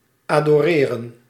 Ääntäminen
IPA: [a.dɔ.ʁe]